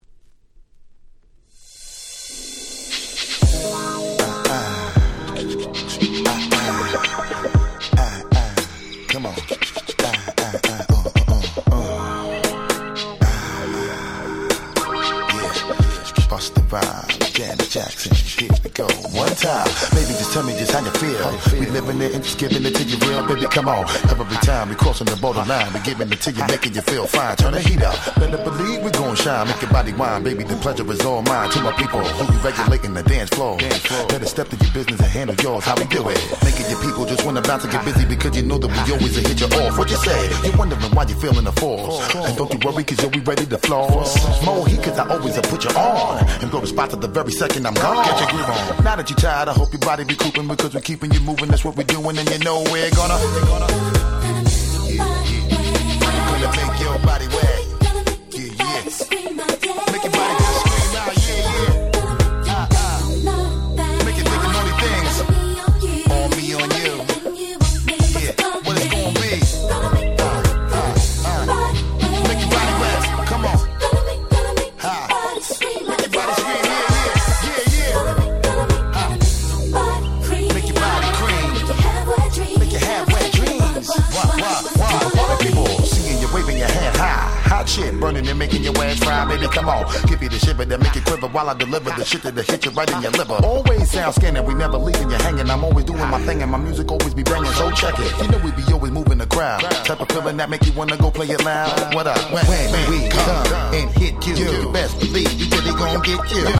99' Super Hit Hip Hop !!